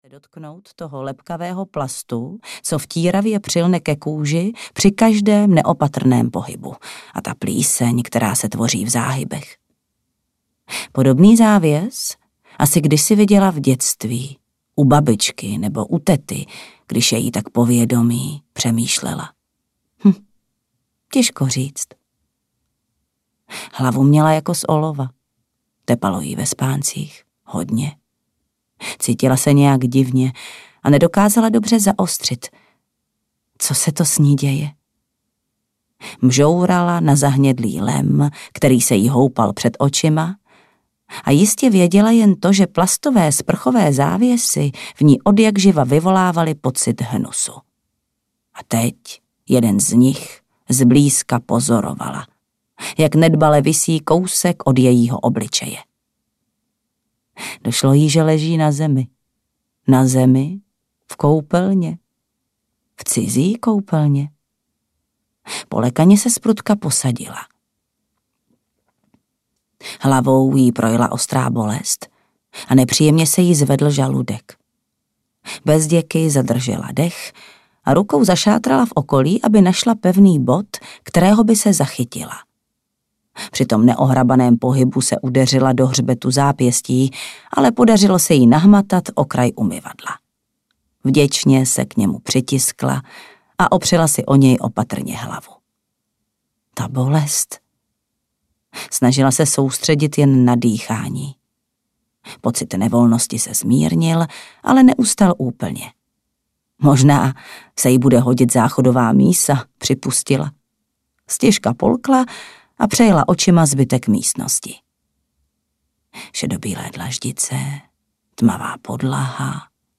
Zítřek ti nikdo neslíbil audiokniha
Ukázka z knihy